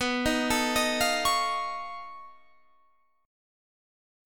Listen to B9b5 strummed